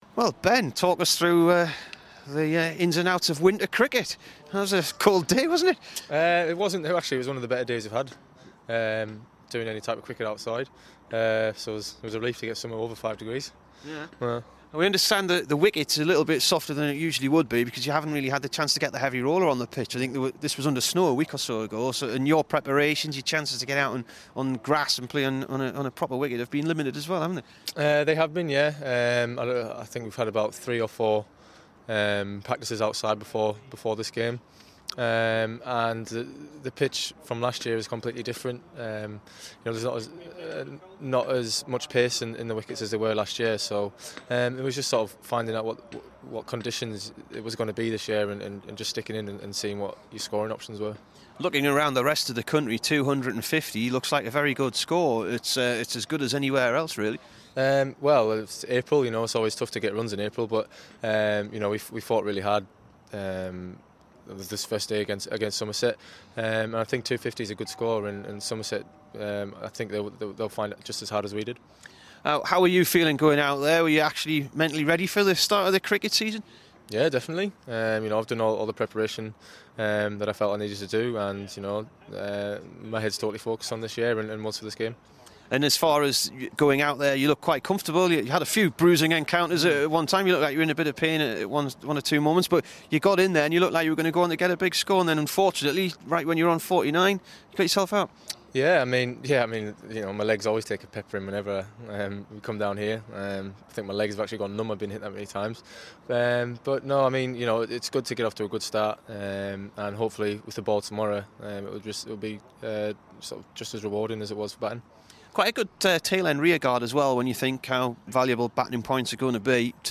BEN STOKES INTERVIEW